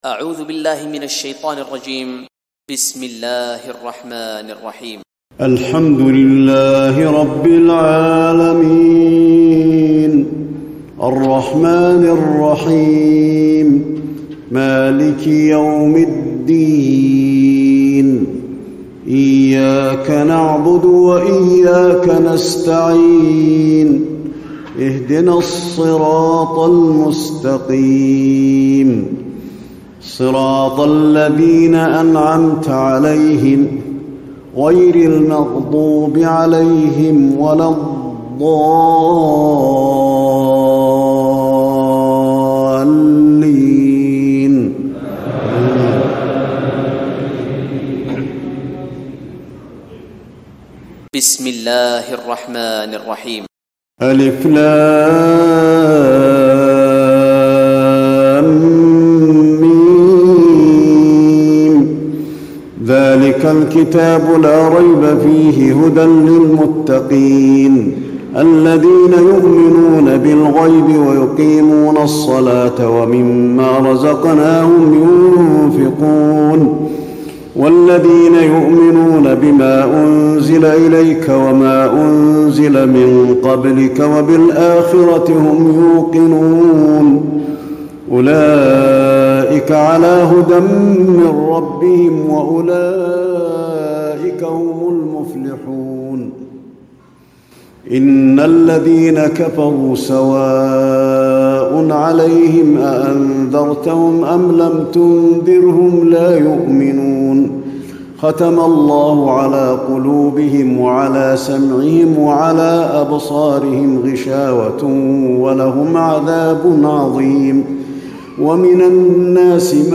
تراويح الليلة الأولى رمضان 1436هـ من سورة البقرة (1-77) Taraweeh 1st night Ramadan 1436 H from Surah Al-Baqara > تراويح الحرم النبوي عام 1436 🕌 > التراويح - تلاوات الحرمين